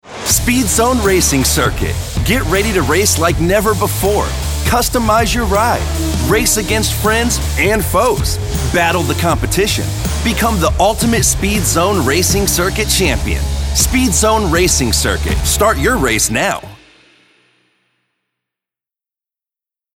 announcer, confident, cool, guy-next-door, millennial, perky, Straight Forward, upbeat